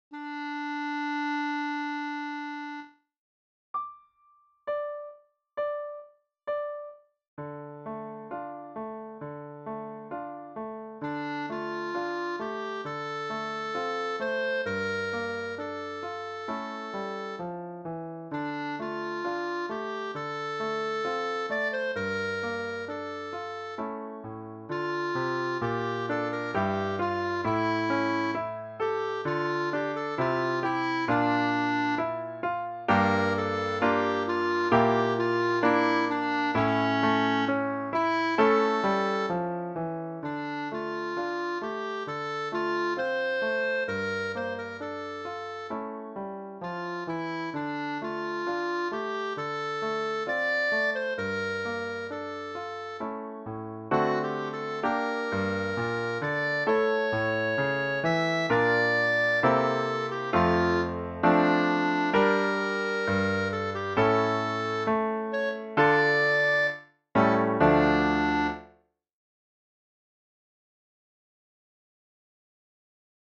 Ici, on pourra acceder à une version accompagnée des mélodies et chansons apprises lors de nos cours.
Une autre mélodie en mode mineur pour travailler la syncope de noire (ti-taa-ti).